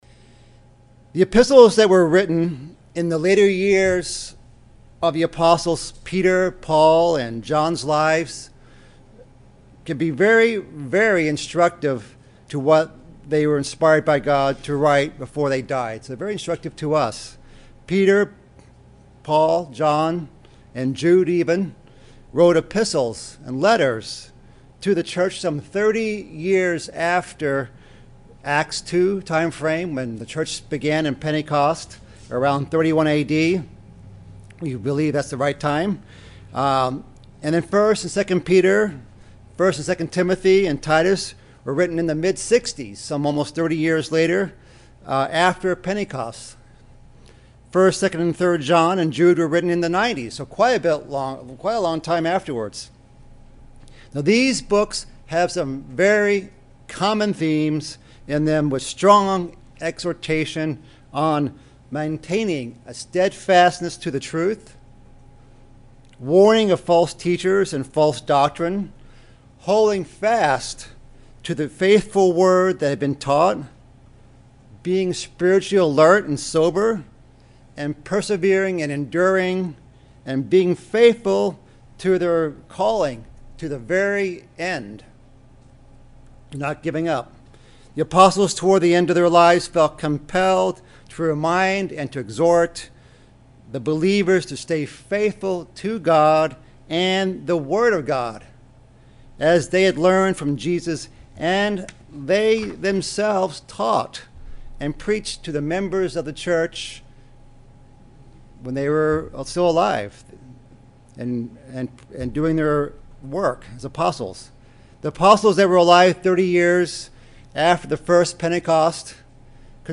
Sermons
Given in Beloit, WI Chicago, IL